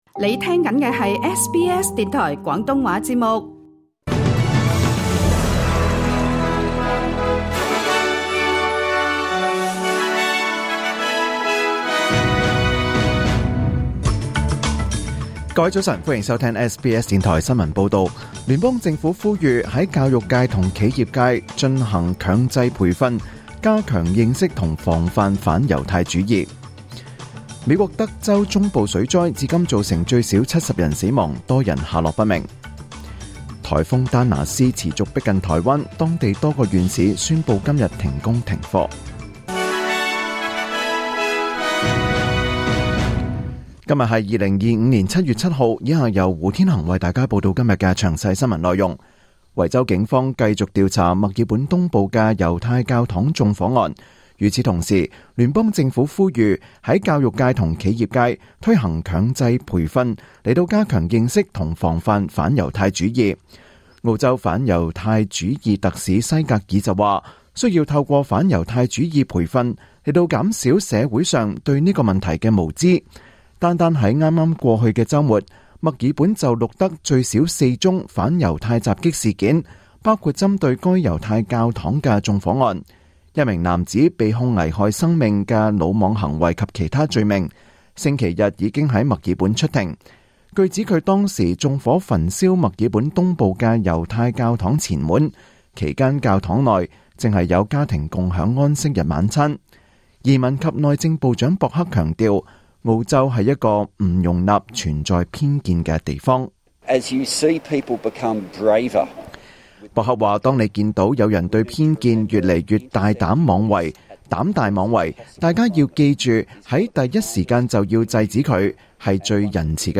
2025年7月7日SBS廣東話節目九點半新聞報道。